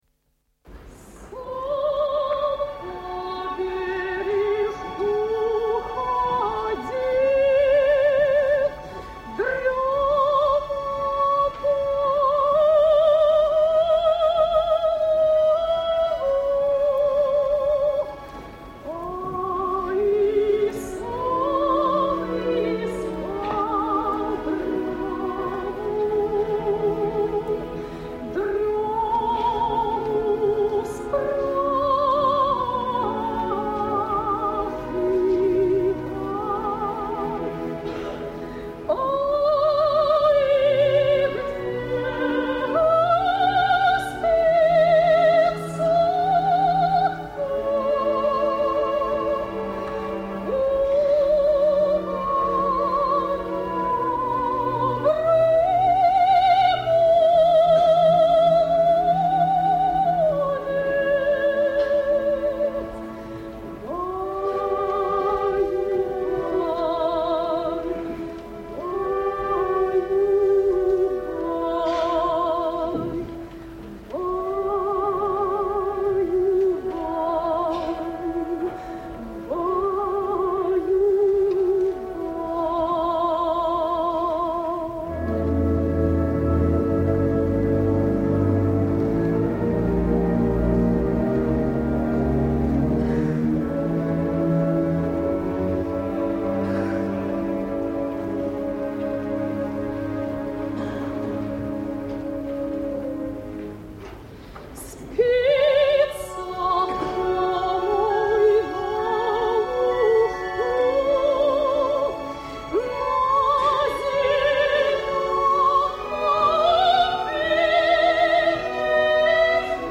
Жанр: Opera
В исполнении отечественных певиц.